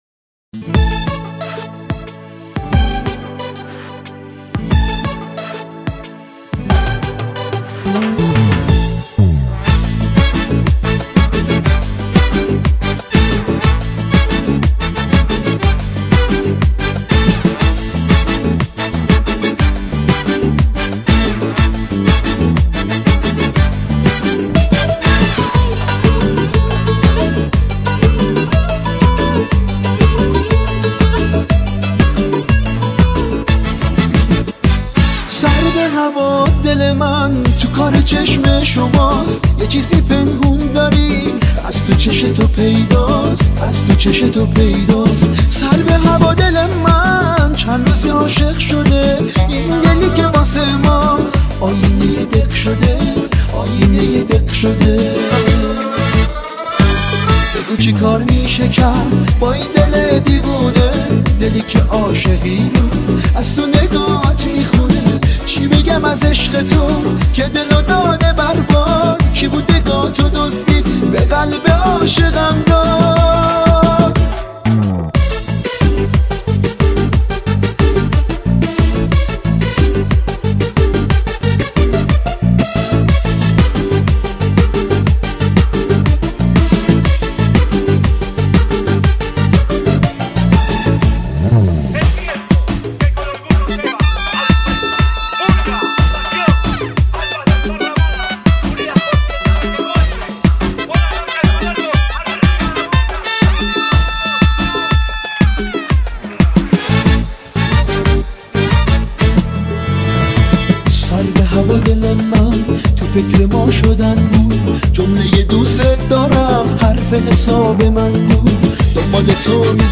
Категория: Иранские